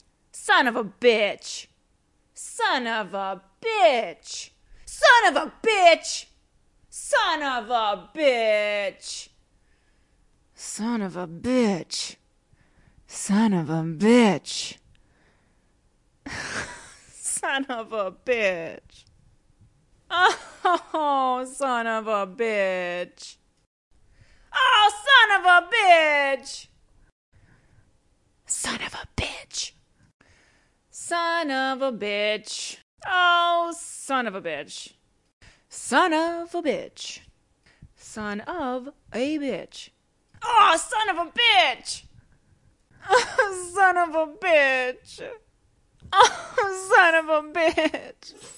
女性视频游戏配音 " 婊子养的
性感，愤怒，快乐，有趣，悲伤...... 使用USB Mic和Audacity录制。
Tag: 讲话 谈话 声音 女孩 性感 英语 女性 讲话 美国 声乐 女人 视频游戏